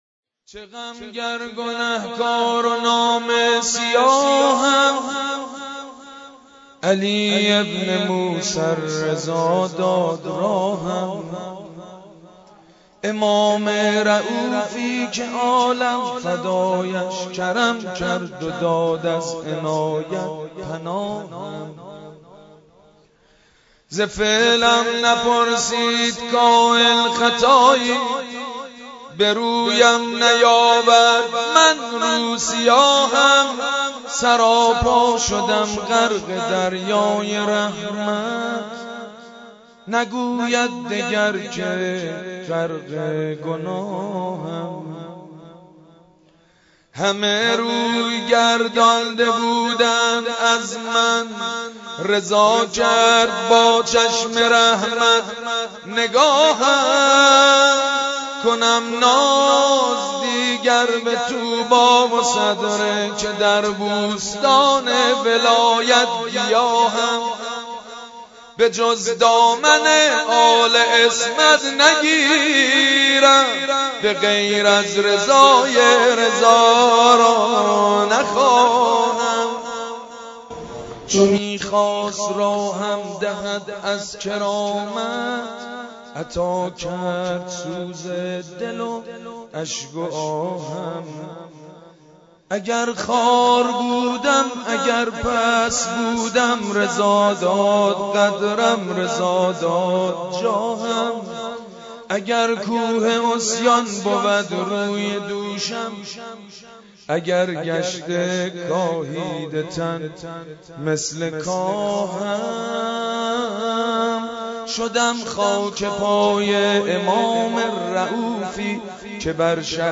متن مولودی مدح ولادت امام رضا (ع) به مناسبت دهه ی کرامت